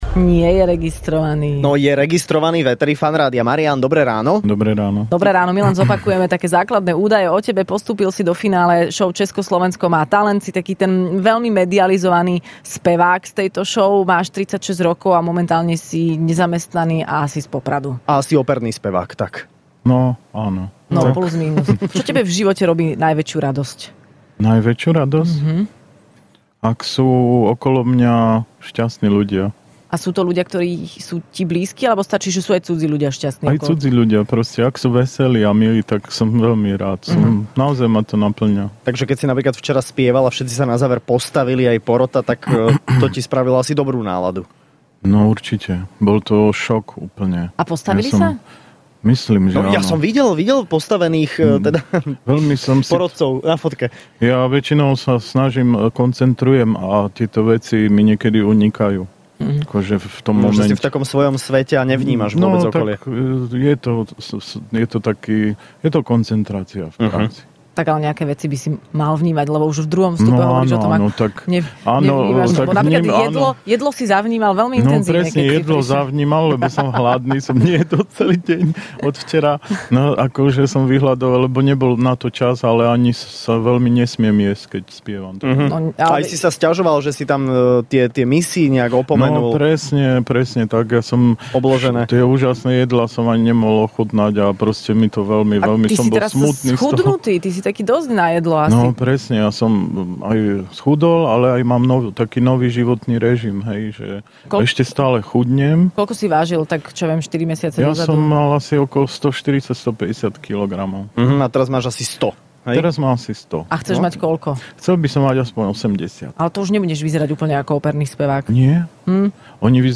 Do Rannej šou prišli dvaja postupujúci semifinalisti zo šou Česko - Slovensko má talent